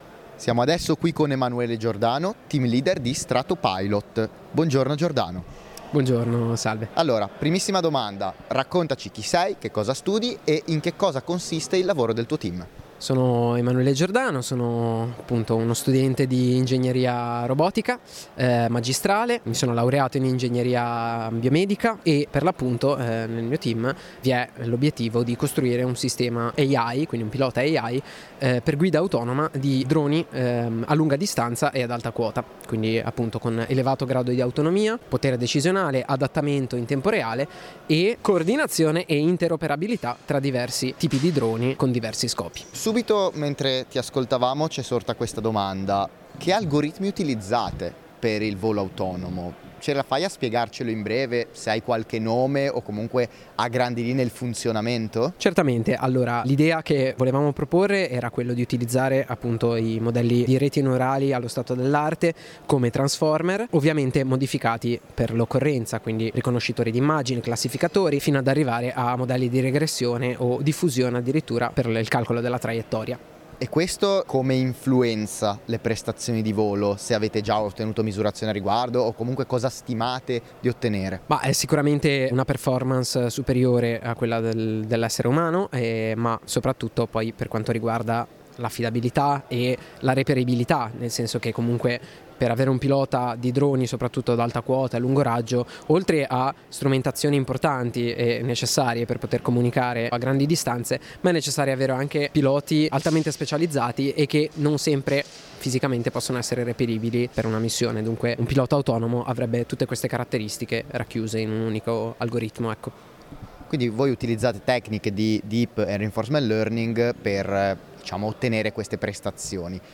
Intervista di